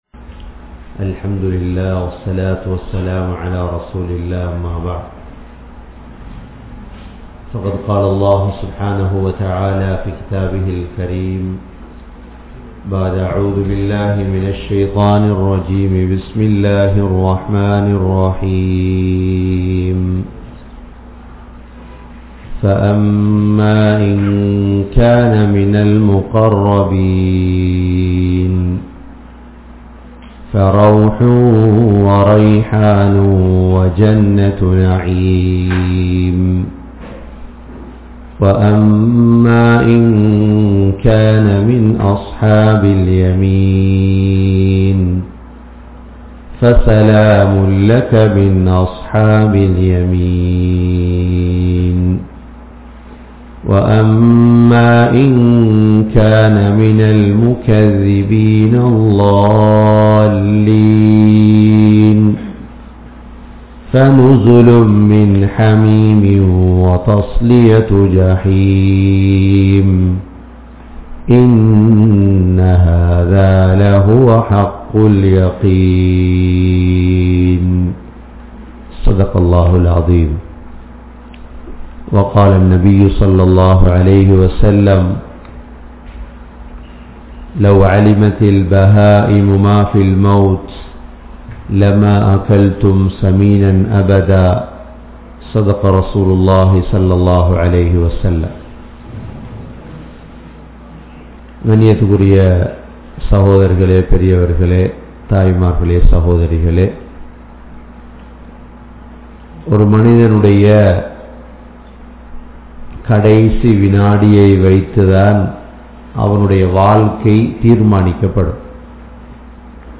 Maranththin Nimidam (மரணத்தின் நிமிடம்) | Audio Bayans | All Ceylon Muslim Youth Community | Addalaichenai
Canada, Toronto, Thaqwa Masjidh